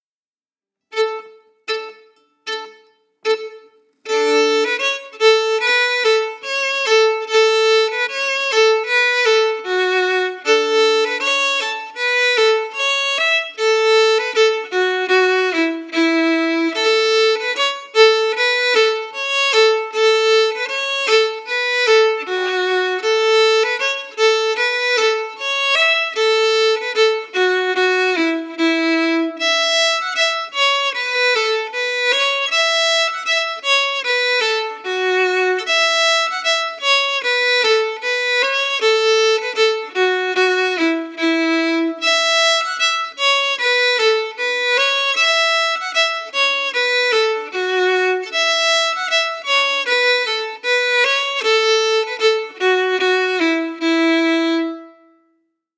Key: A
Form: Polka
played slowly for learning